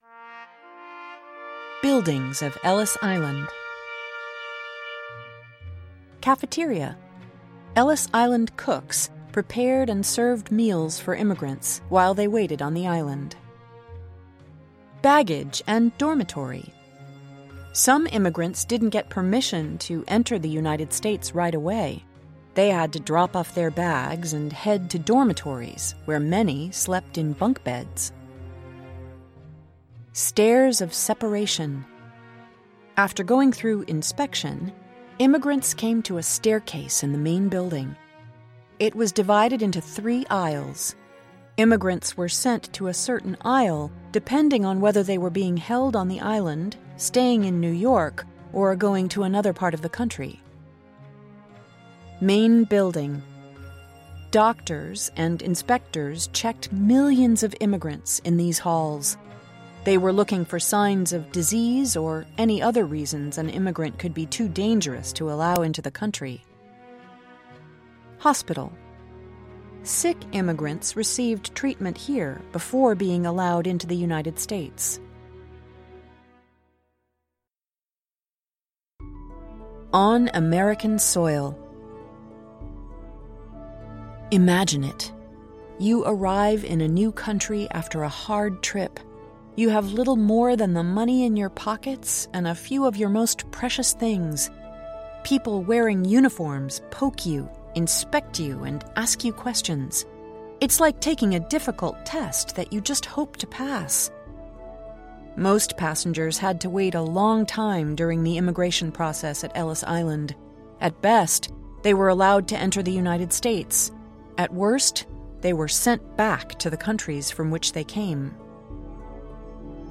Acclaimed narrators present these Level 3 readers providing accessible, wide-ranging information on timely topics for independent readers.